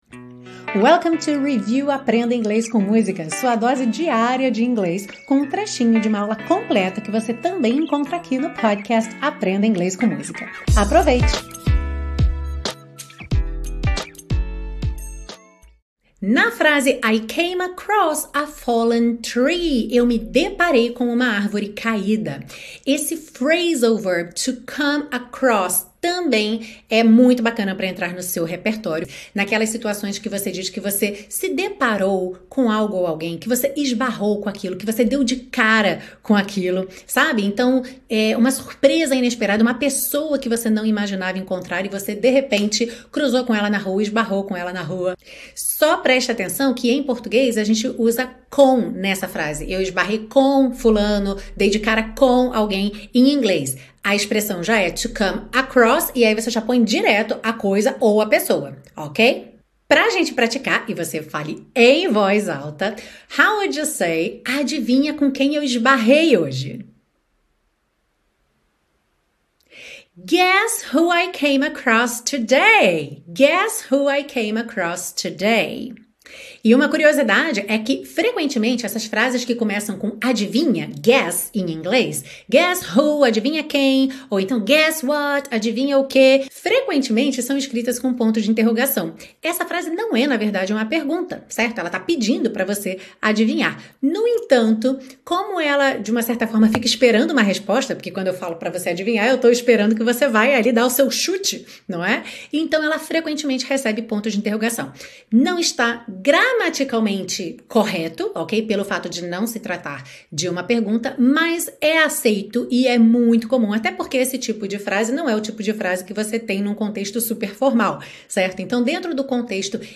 1 Why Face-to-Face Beats Online: English Dating Conversation Ep 833 12:13 Play Pause 21h ago 12:13 Play Pause Play later Play later Lists Like Liked 12:13 This listening lesson uses a fascinating 2025 study on how couples meet to train your ear and accelerate your path to English fluency. In this video, you will master essential relationship vocabulary like "bond", "commitment", and "choice overload", practice comprehension of a UK accent and natural pacing, and learn critical thinking phrases like "…